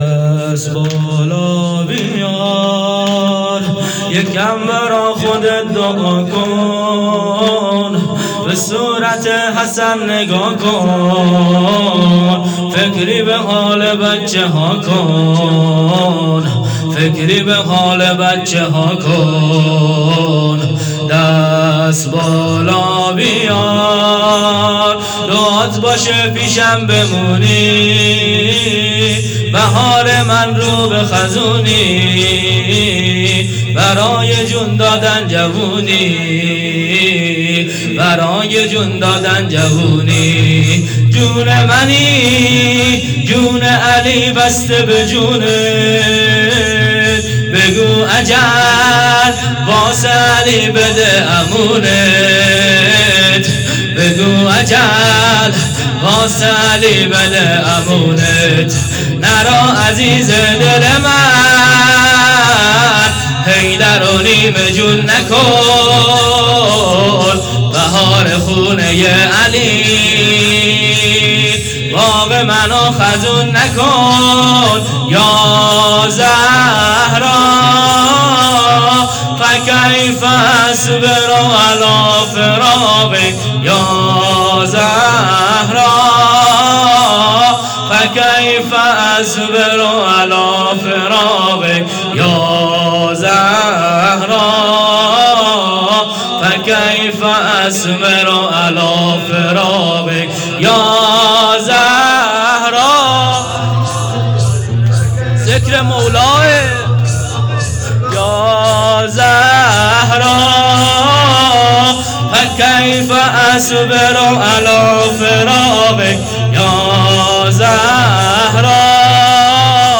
شب اول دهه دوم فاطمیه 1442
زمینه فاطمیه